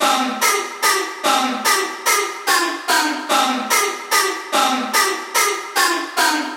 Tag: 146 bpm Trap Loops Vocal Loops 1.11 MB wav Key : Unknown